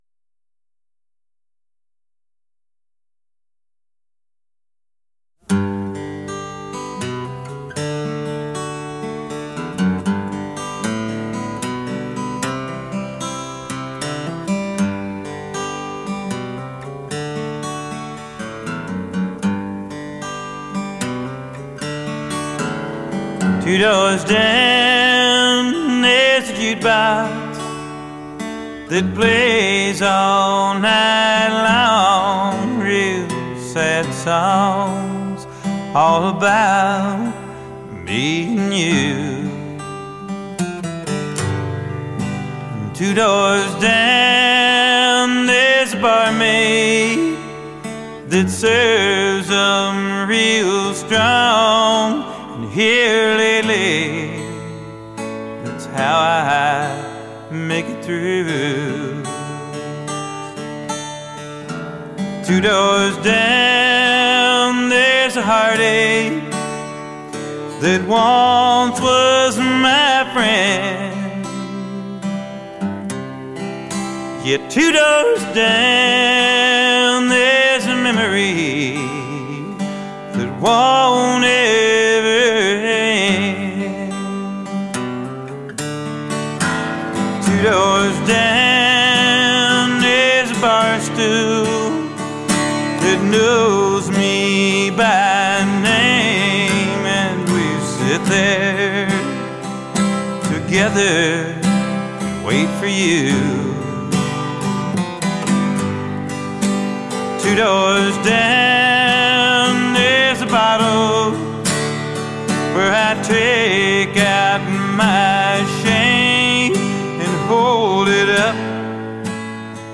The funny part of this very sad, cry in your beer song.
just him and his guitar.